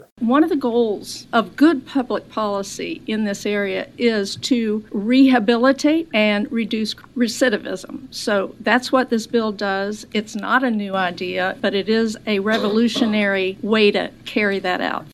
A state lawmaker from Shelby County is proposing a college option for state inmates.  Jennifer Decker says Michigan is having success with their program.